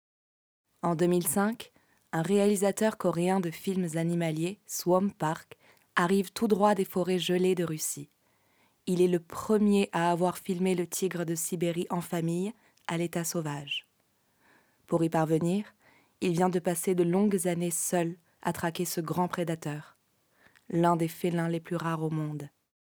Voix off
20 - 45 ans - Mezzo-soprano